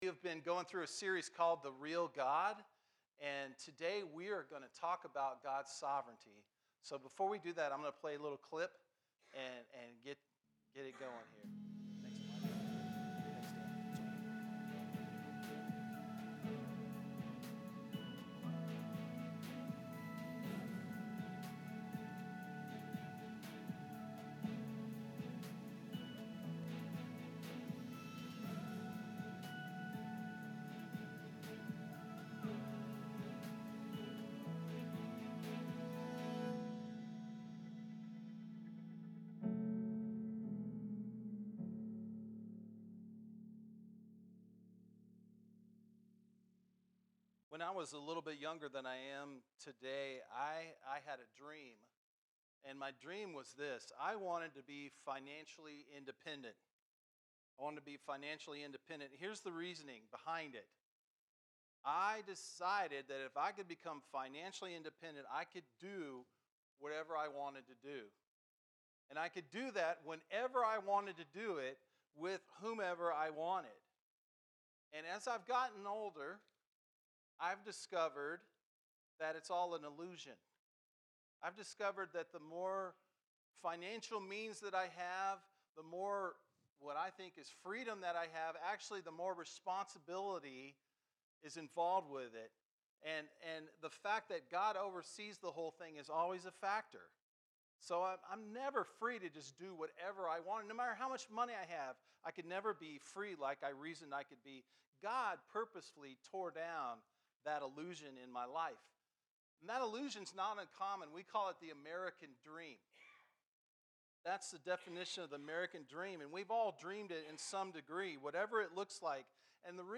Sermons - Chambers Creek